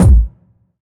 GS Phat Kicks 012.wav